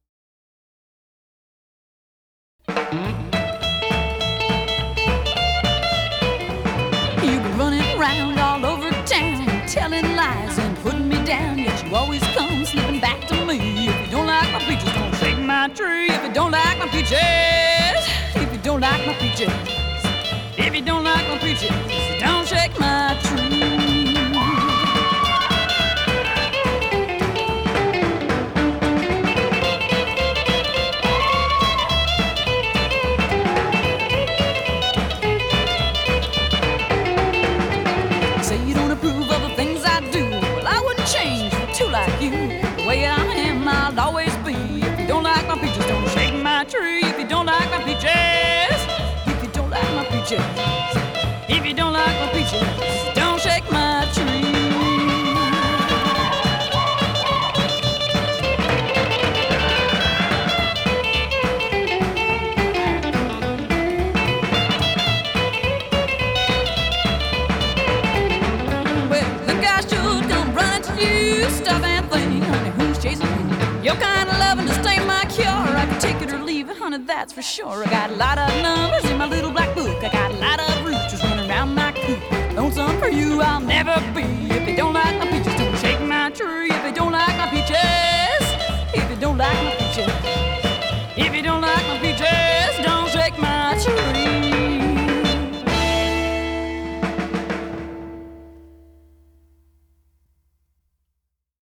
Жанр: Rock
Стиль: Rockabilly